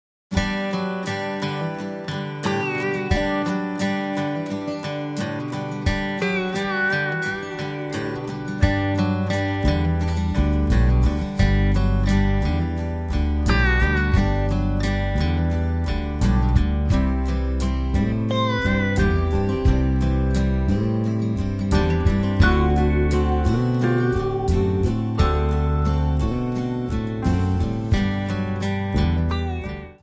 Backing track files: 1970s (954)